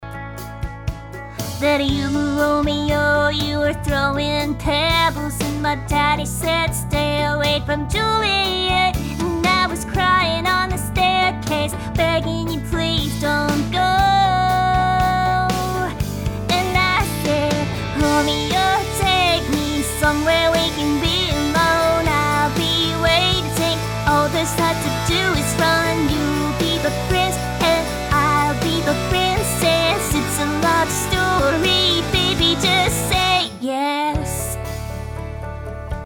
RVC模型 珊迪声音模型
珊迪的声音通常比较清脆、尖细，带有一种轻快和活泼的语调，这完全符合她活泼好动、乐观向上的性格。同时，她的声音也透露出一种坚定和自信，这反映了她聪明、勇敢和独立的个性。
推理效果试听